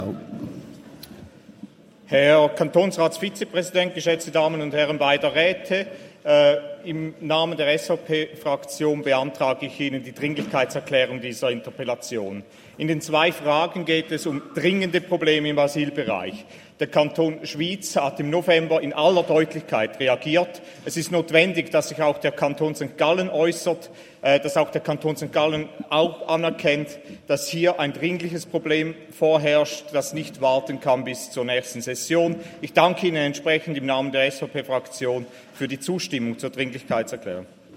Session des Kantonsrates vom 2. bis 4. Dezember 2024, Wintersession
3.12.2024Wortmeldung